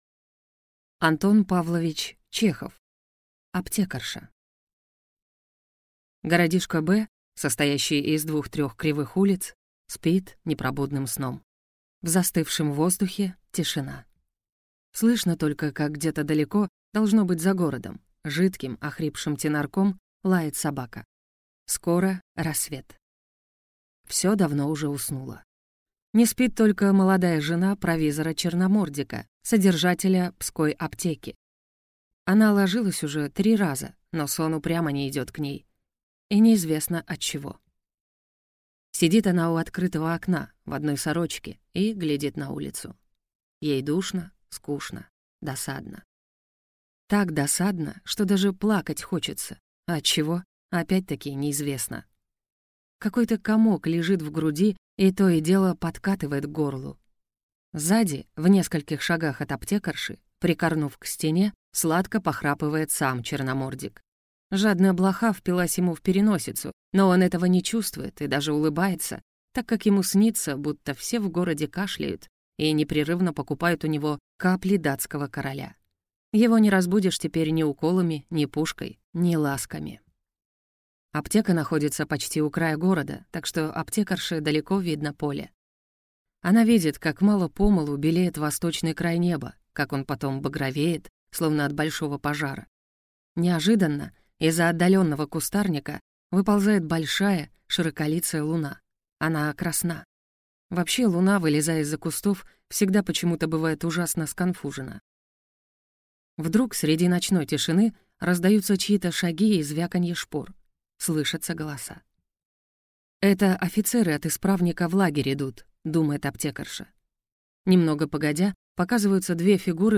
Aудиокнига Аптекарша Автор Антон Чехов Читает аудиокнигу